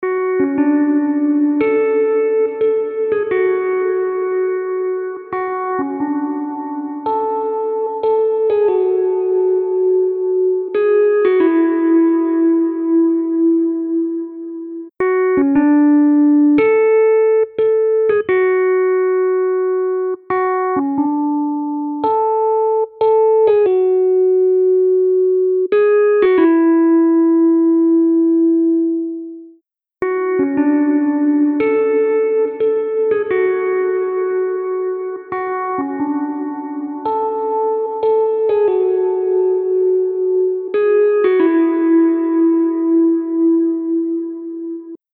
UltraReverb | Keys | Preset: Empty Garage Level E
UltraReverb-Eventide-Keys-Empty-Garage-Level-E.mp3